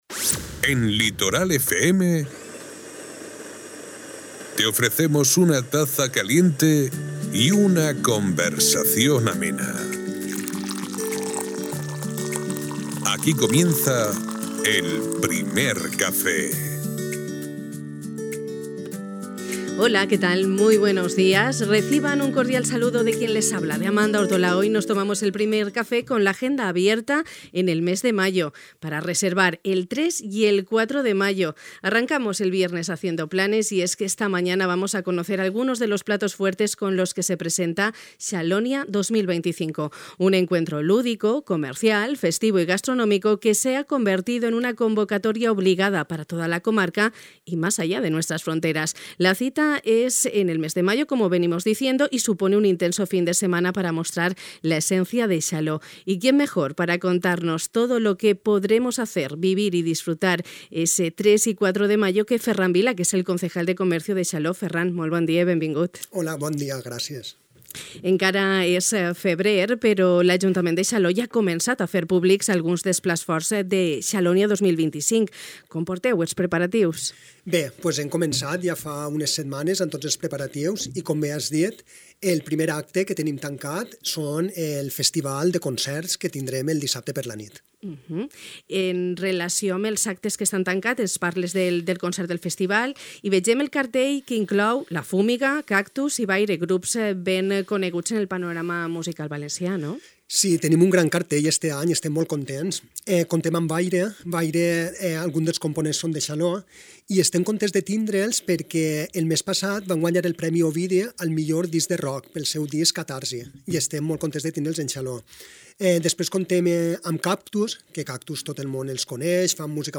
El Primer Cafè de Ràdio Litoral de hui divendres ens ha ajudat a fer plans ja de cara al mes de maig. Aprofitant que s'han donat a conèixer alguns dels plats forts de la programació de Xalònia 2025 hem conversat amb el regidor de Comerç de Xaló, Ferran Vila, que ens ha avançat alguns dels continguts que oferirà la 13a edició d'aquesta consolidada convocatòria.